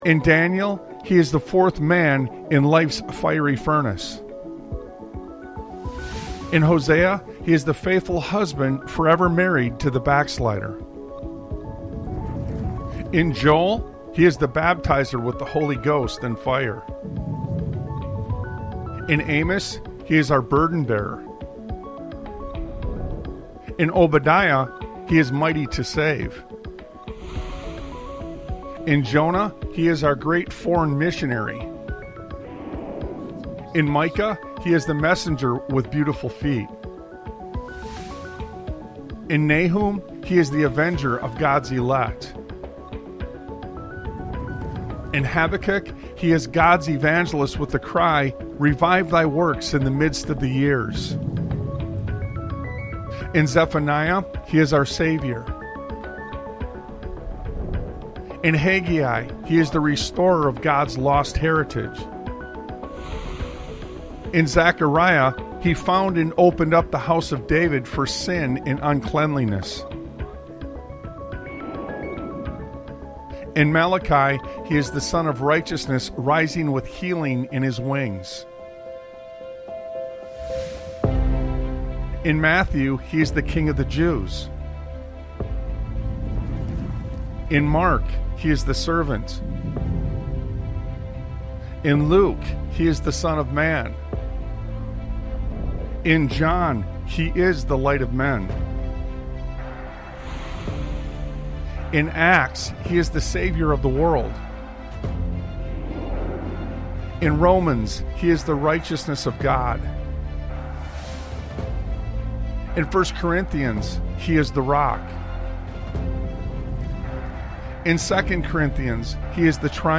Talk Show Episode, Audio Podcast, Sons of Liberty Radio and His Fingerprints Are Upon Everything on , show guests , about His Fingerprints Are Upon Everything, categorized as Education,History,Military,News,Politics & Government,Religion,Christianity,Society and Culture,Theory & Conspiracy